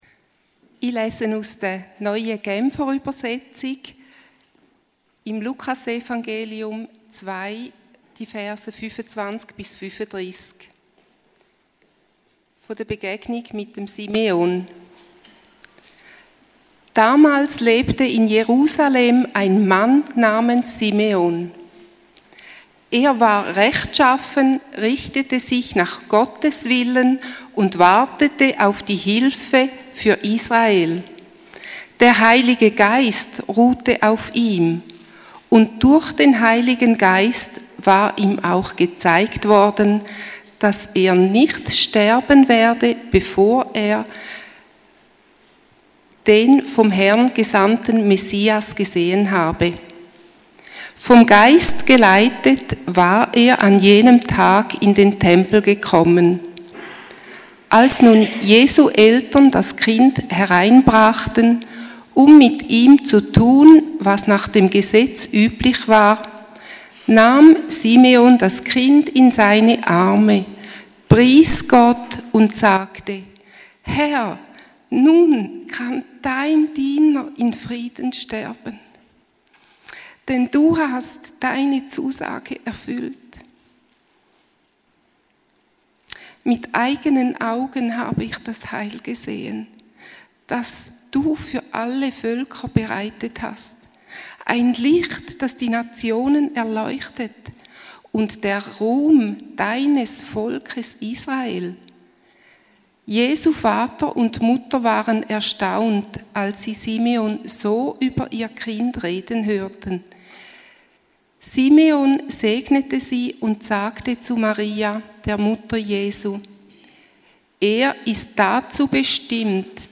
Podcast FEG Langenthal - Gottesdienst zum 2. Advent: das Warten des Simeon